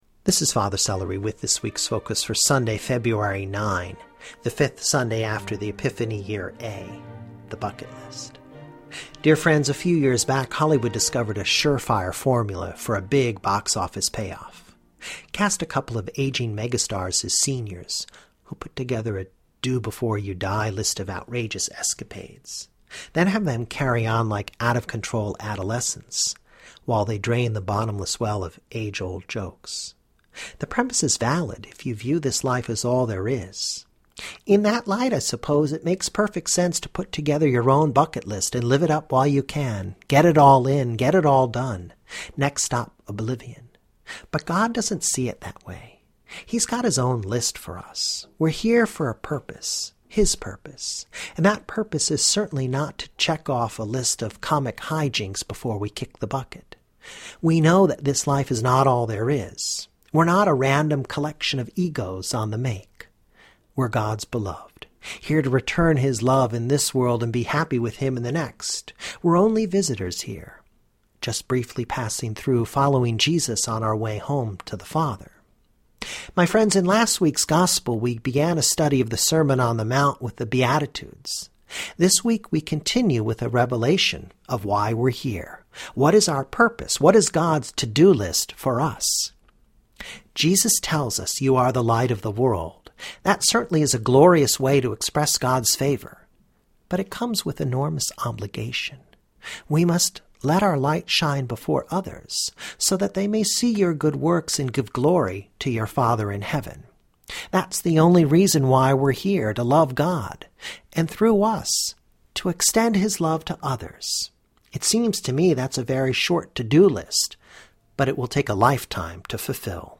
Brief Audio reflection on Matthew 5:13-20.